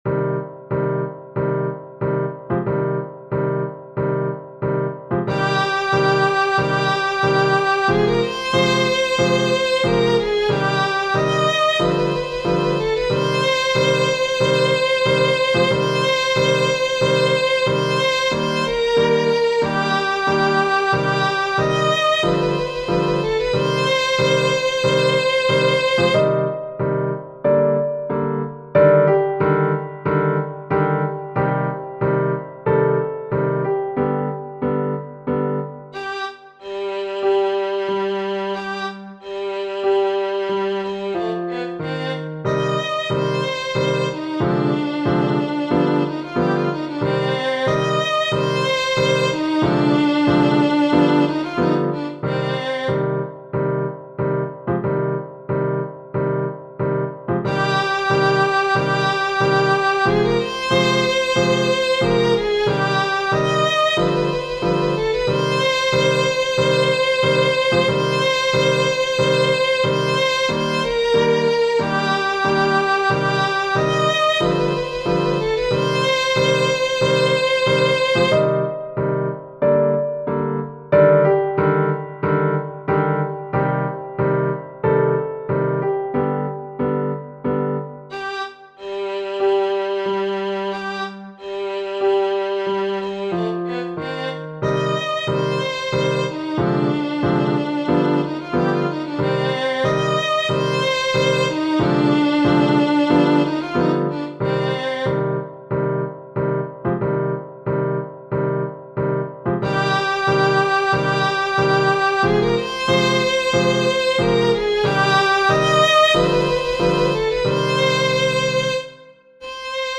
Schubert, F. Genere: Classica Questo Piano Trio, composto nel 1827, è una delle ultime opere di Schubert. L'accompagnamento in forma di marcia del II movimento dà alla melodia una intensità dolorosa e ossessiva.